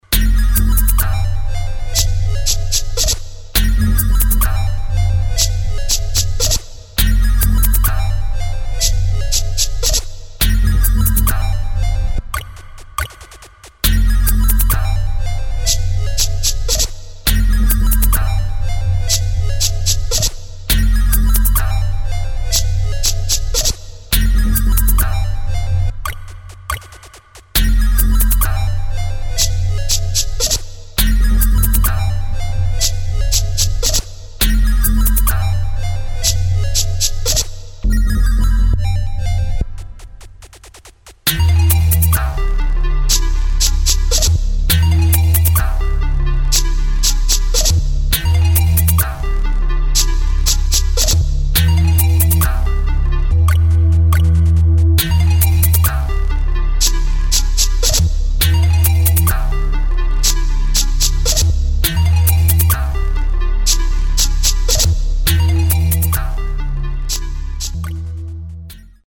[ DUBSTEP | BASS | EXPERIMENTAL ]